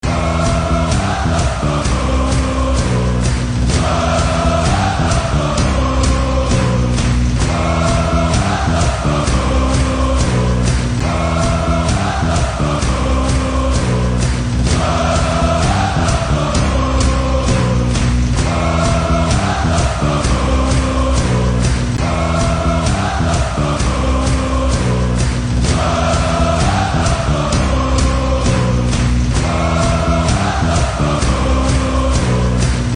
suoneria